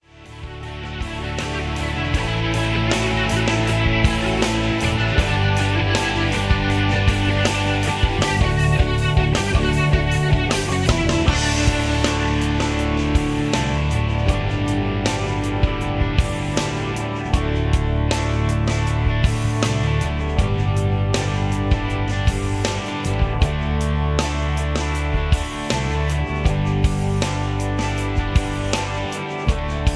karaoke, rock